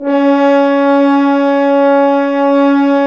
Index of /90_sSampleCDs/Roland L-CD702/VOL-2/BRS_F.Horns 2 f/BRS_FHns Dry f